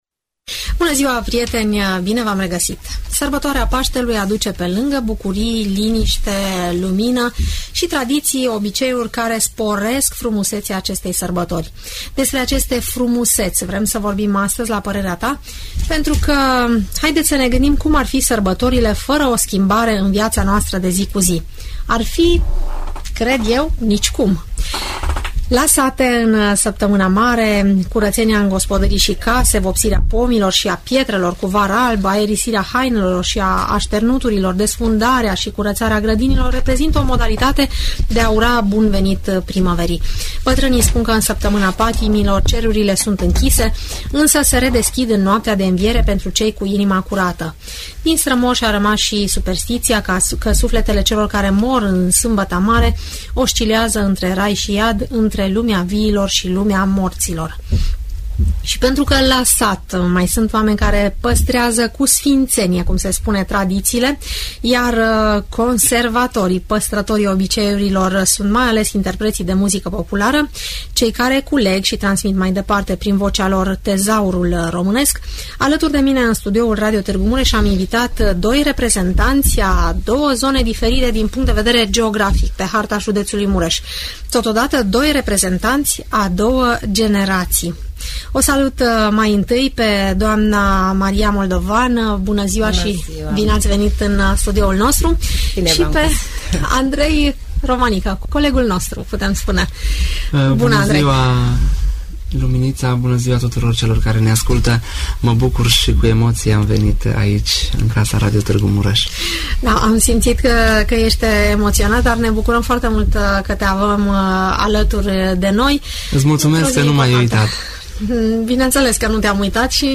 cu doi îndrăgiți interpreți de muzică populară